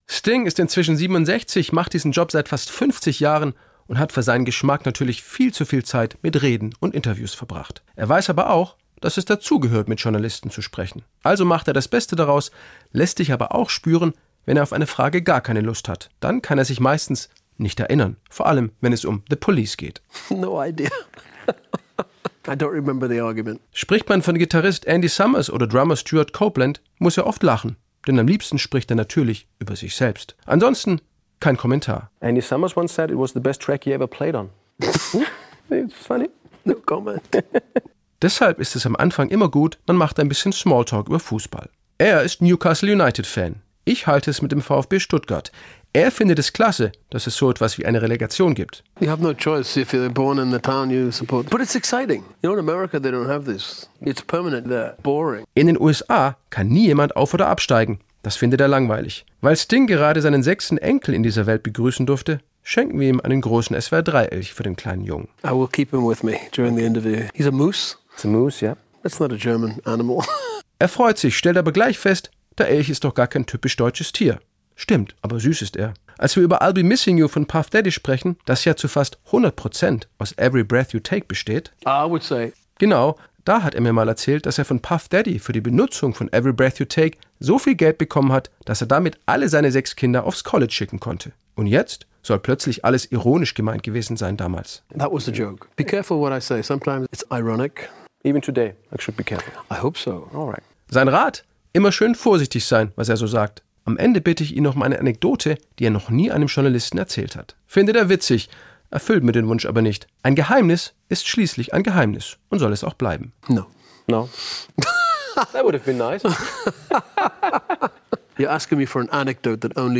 Interview mit Sting Teil 3: Sting überrascht – hier sind die "Outtakes"